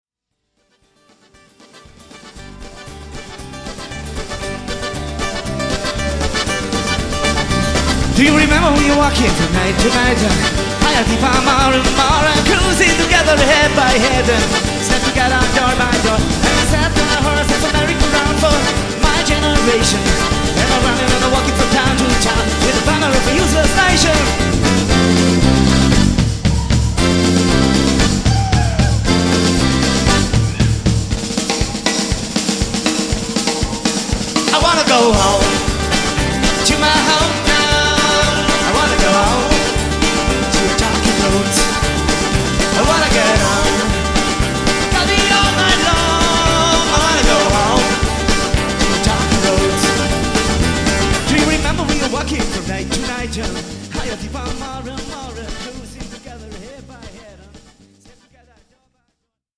Rec. live 1998